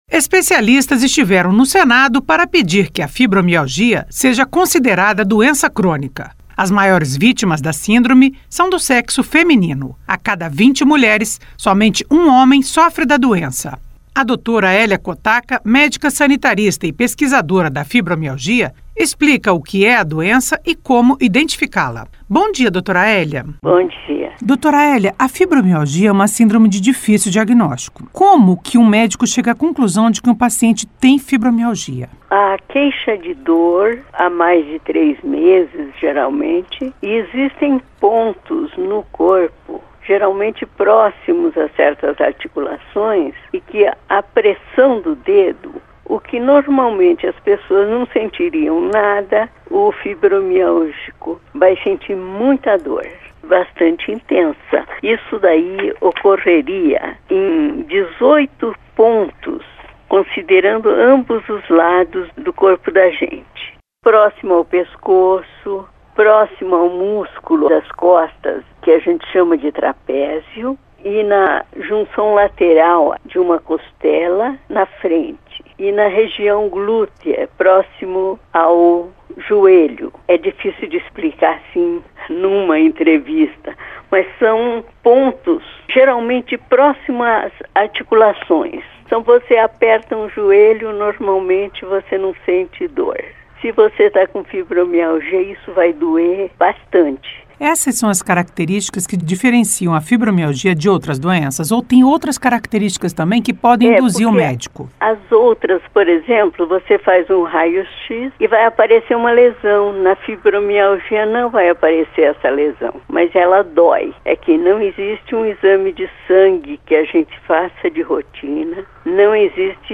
*Entrevista originalmente veiculada em 1º de setembro de 2016